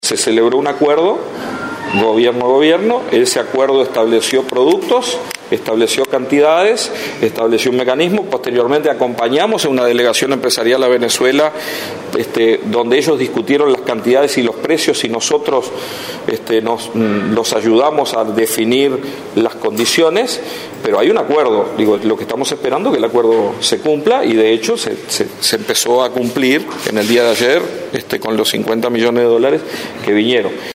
Venezuela depositó los primeros 50 millones de dólares del fideicomiso firmado con Uruguay según anunció el ministro de Ganadería, Agricultura y Pesca, Tabaré Aguerre, en declaraciones a la prensa tras su comparecencia ante la Comisión de Hacienda del Senado.